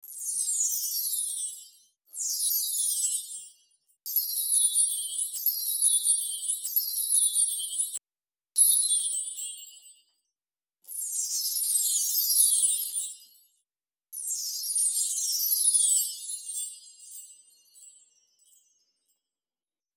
Download Sparkle sound effect for free.
Sparkle